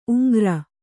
♪ uŋgra